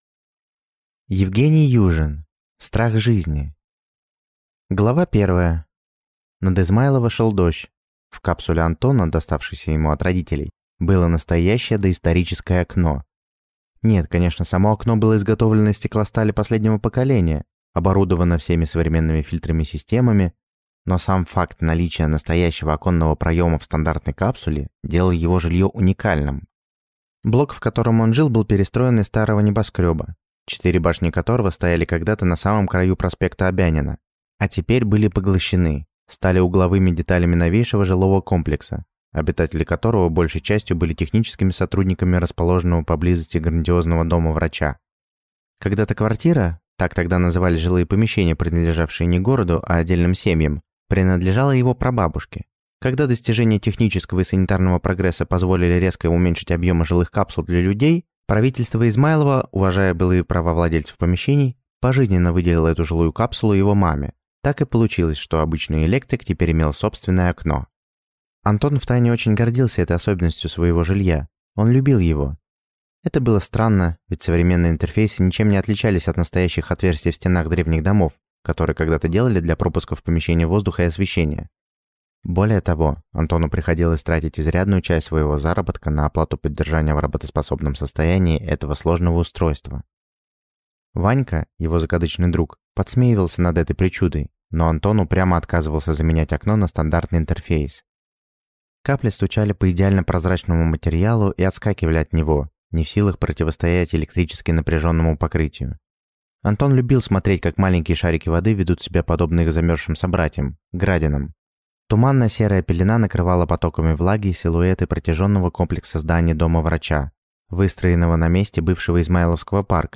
Аудиокнига Страх жизни | Библиотека аудиокниг